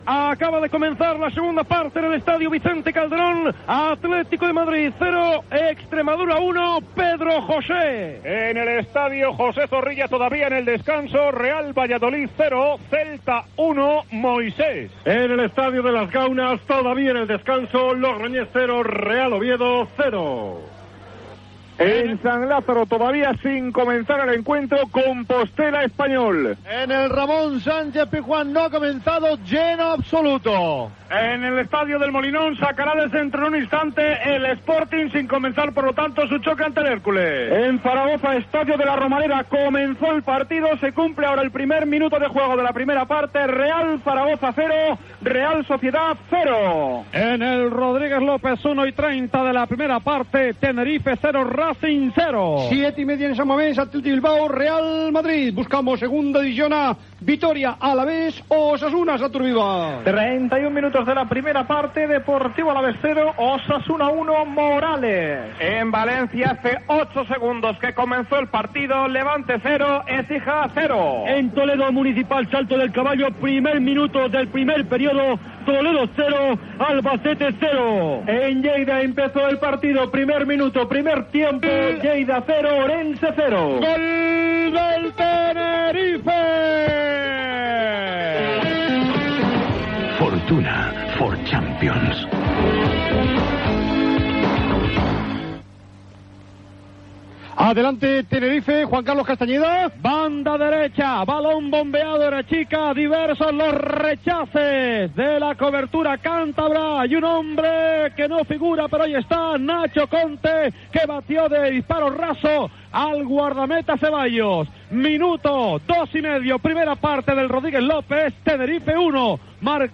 Narració del partit Sevilla - Rayo Vallecano.